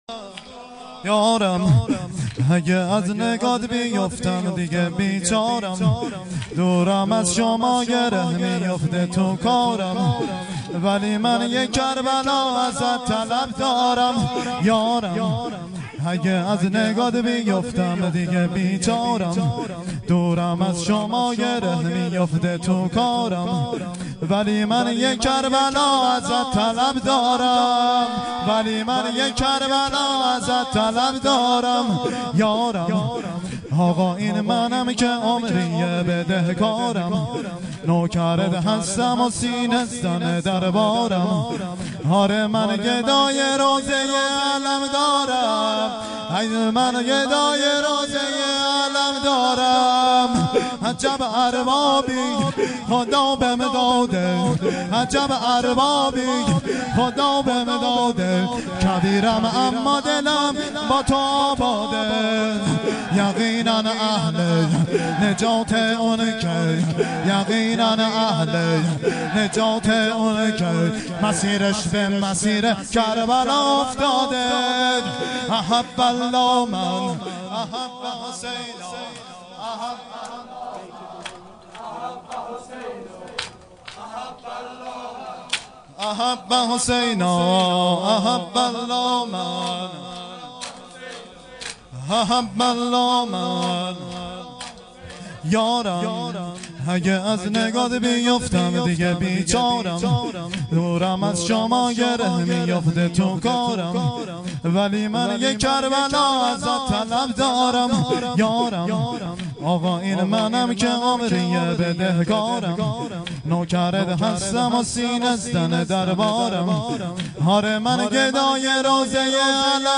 شب چهارم محرم ۱۴۴۱